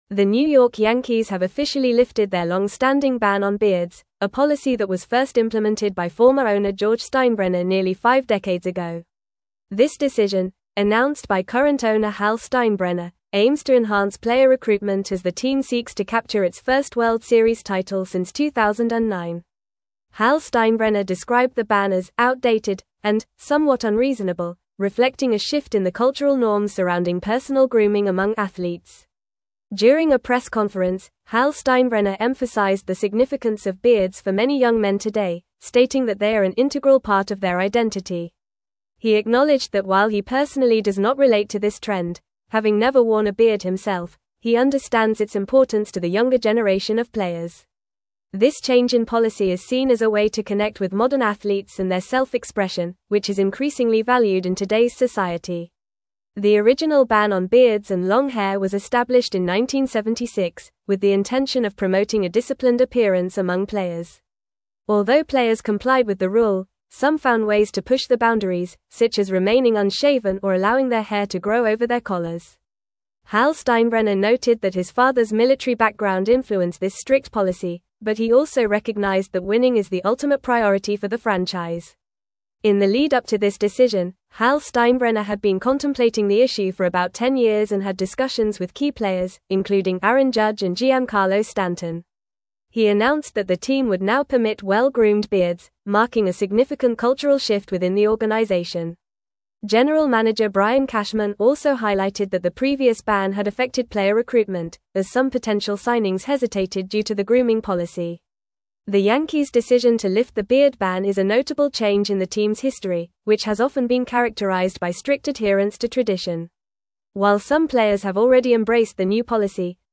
Fast
English-Newsroom-Advanced-FAST-Reading-Yankees-Lift-Longstanding-Beard-Ban-for-Players.mp3